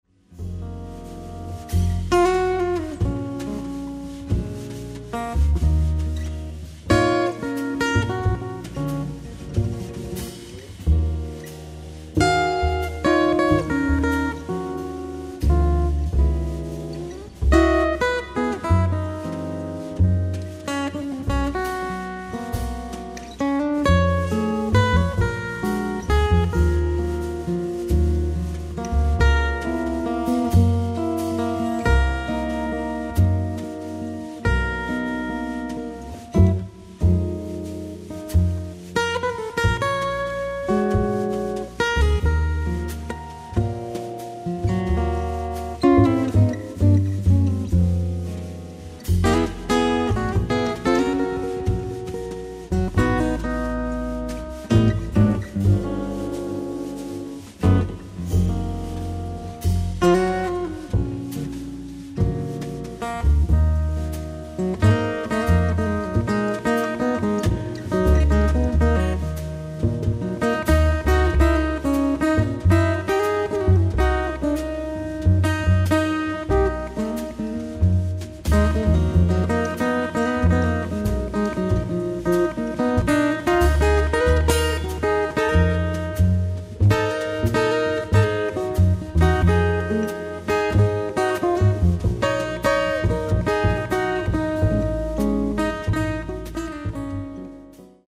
ライブ・アット・ケルン、ドイツ 04/26/2000
※試聴用に実際より音質を落としています。